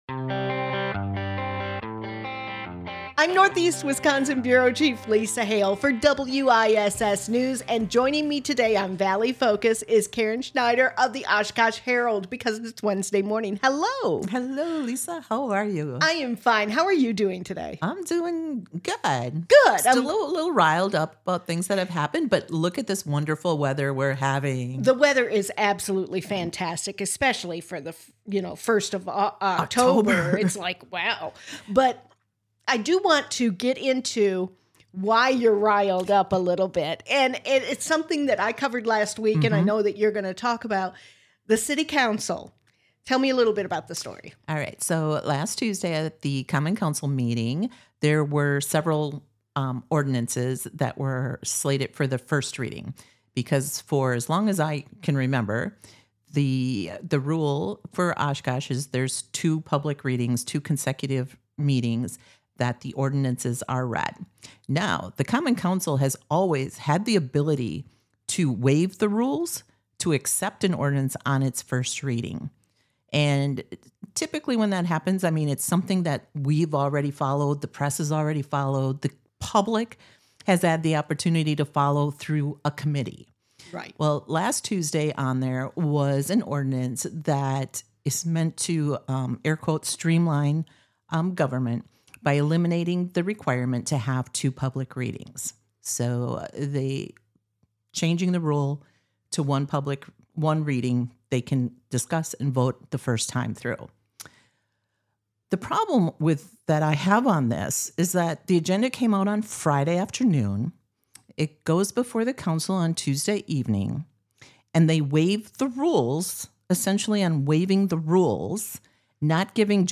Conversations will range from entertainment to government to community involvement and more!